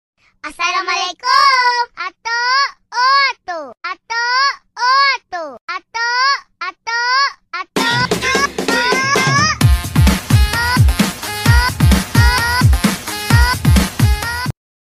Genre: Nada dering remix